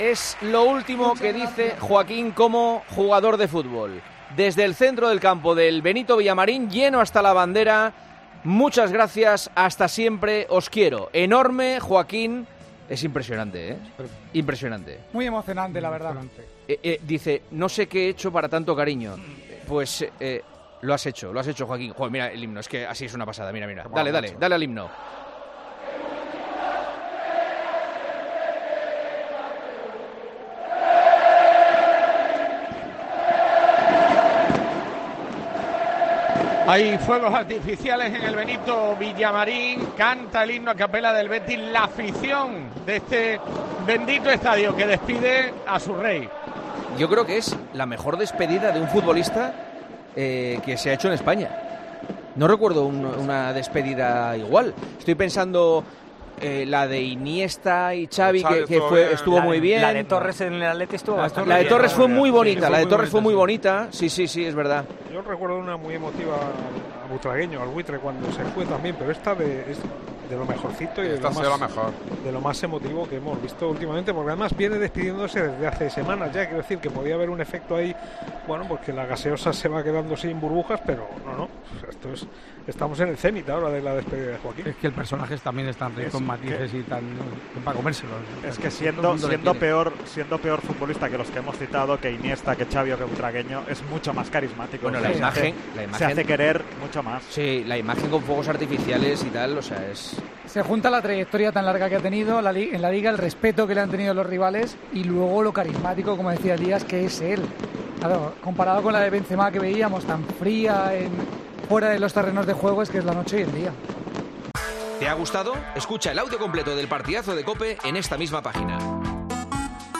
El presentador de 'El Partidazo de COPE' comentó en directo el emocionante final del acto de despedida de la leyenda del equipo verdiblanco en el Benito Villamarín